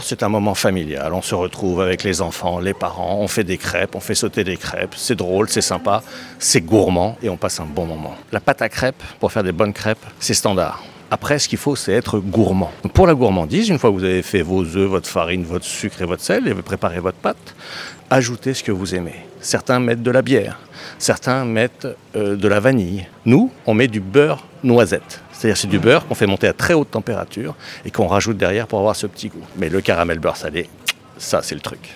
est à notre micro. Pour lui, la base de bonnes crêpes, c'est leur pâte.